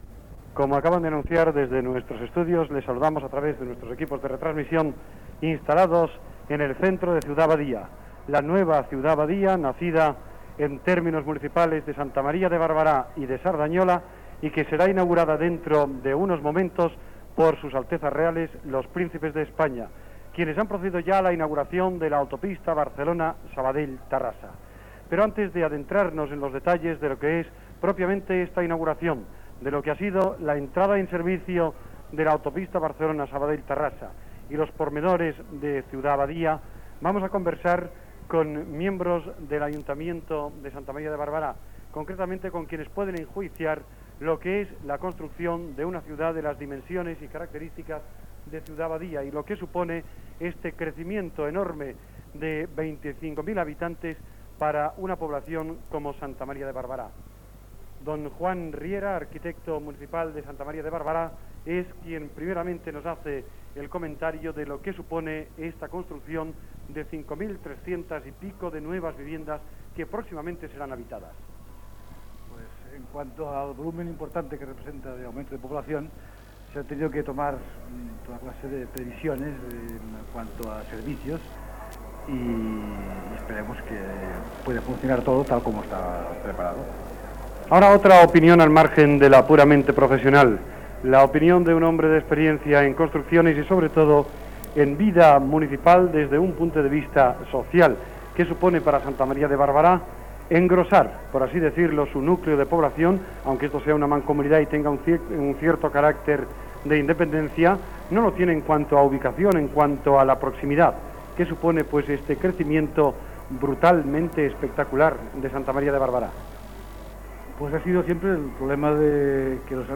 d069d2607c09f3a4f78cc85d8a7651c7a8dc3232.mp3 Títol Ràdio Sabadell EAJ-20 Emissora Ràdio Sabadell EAJ-20 Titularitat Privada local Descripció Transmissió des de la mancomunitat Ciutat Badia en el dia de la seva inauguració, amb entrevistes a membres de l'ajuntament de Santa Maria de Barberà (Joan Riera, i altres). Narració de l'arribada dels Principes de España Juan Carlos de Borbón i Sofia i altres personalitats. Descobriment de la placa del dia de la inauguració.
Paraules d'un dels veïns, del president de la mancomunitat i del príncep Juan Carlos
Informatiu